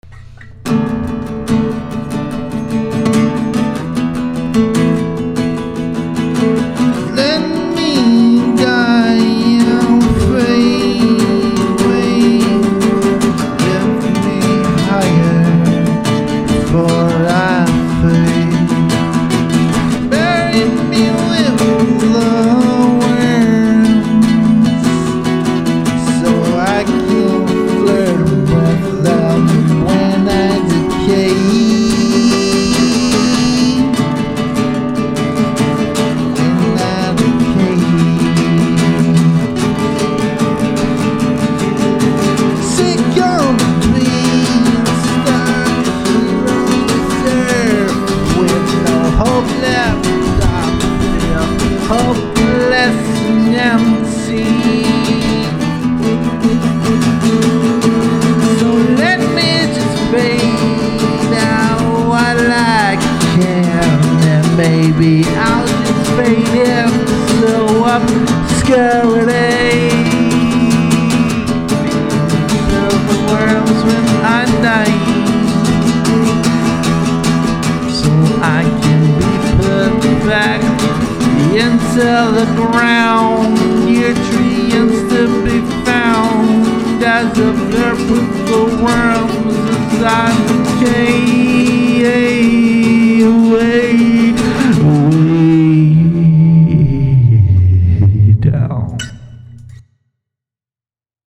just another sheep song
vocal guitar